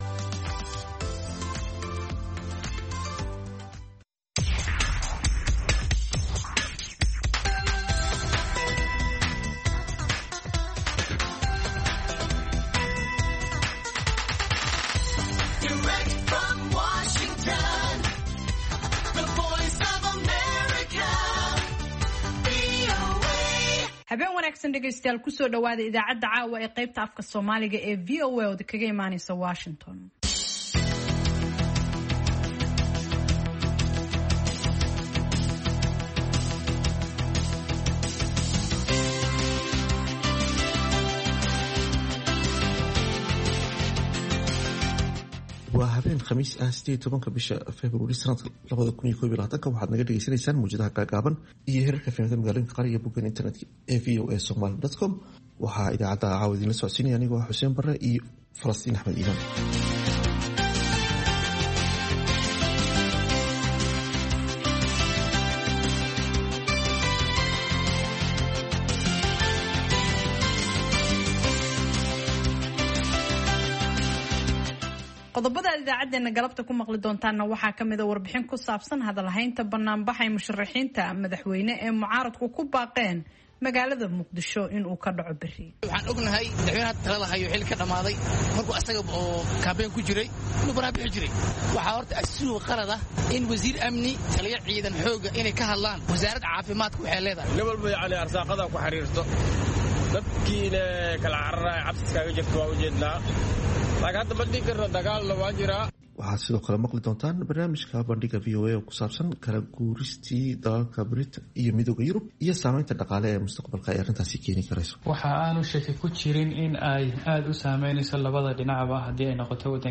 Idaacadda Fiidnimo ee Evening Edition waxaad ku maqashaan wararkii ugu danbeeyey ee Soomaaliya iyo Caalamka, barnaamijyo iyo wareysiyo ka turjumaya dhacdooyinka waqtigaasi ka dhacaya daafaha Dunida.